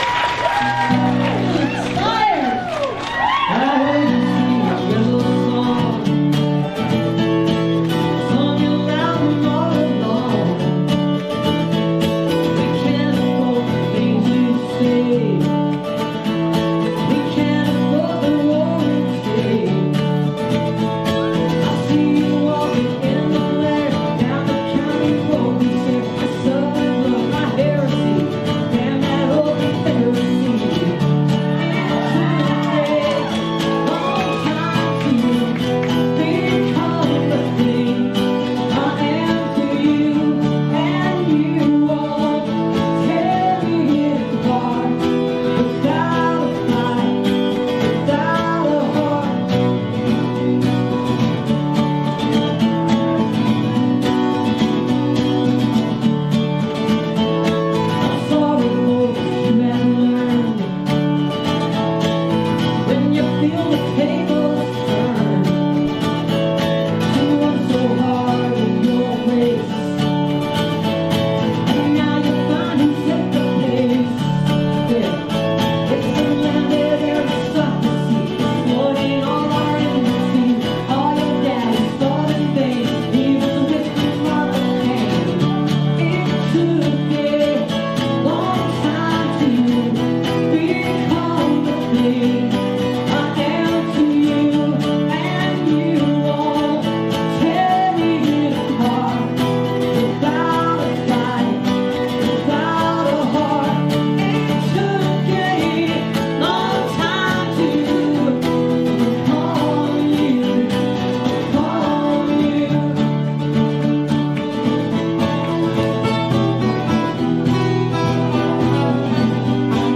(captured from youtube)